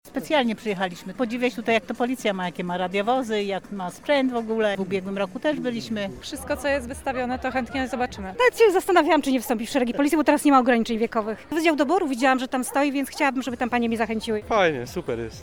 ludzie-policja-.mp3